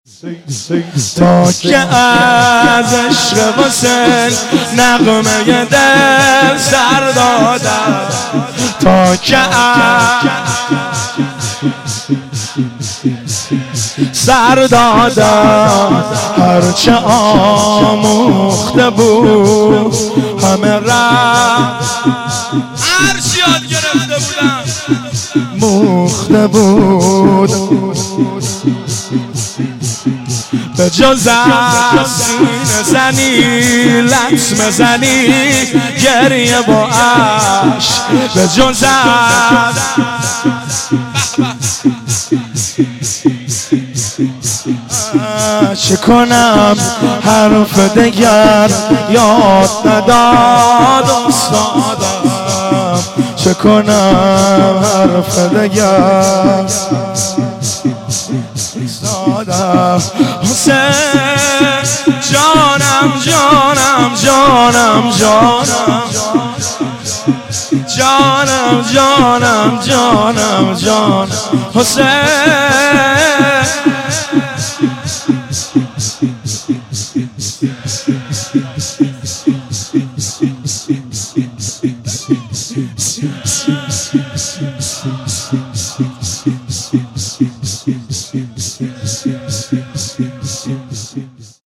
صوت مراسم شب اول محرم ۱۴۳۷ هیئت غریب مدینه امیرکلا ذیلاً می‌آید: